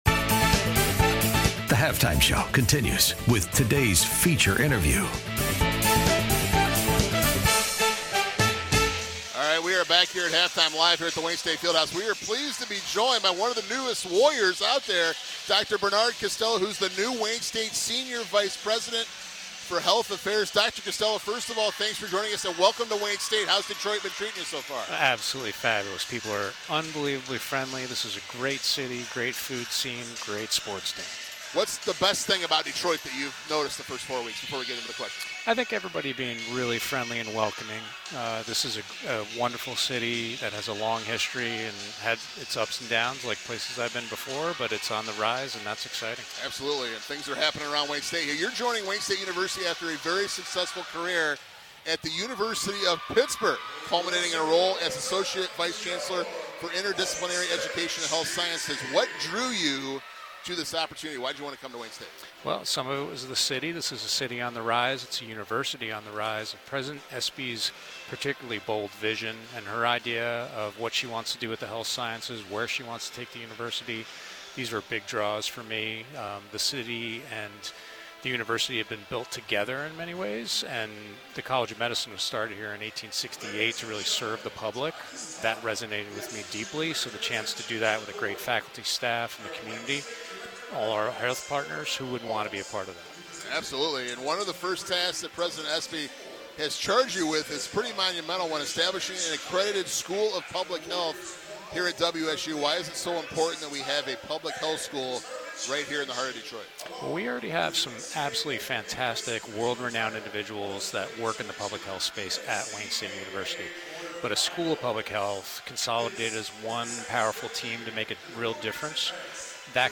Halftime interview